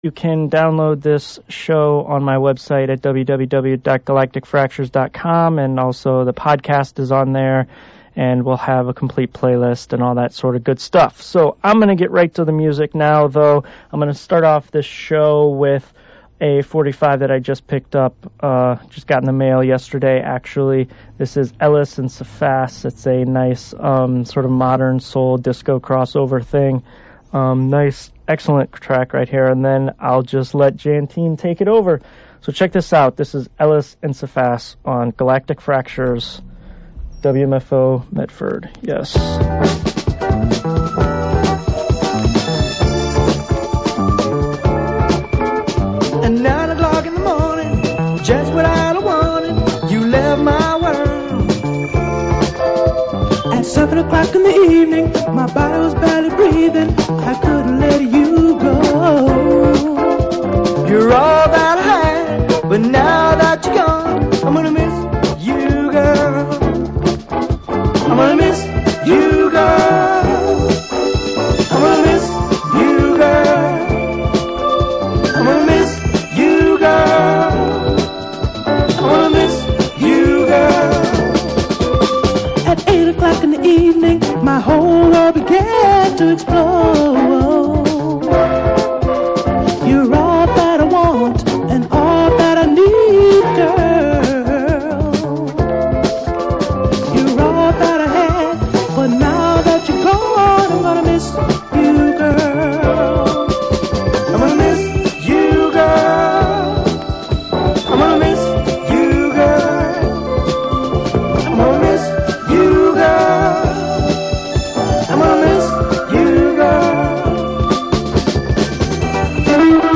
Special guest DJ set
an hour of funk, soul, latin & disco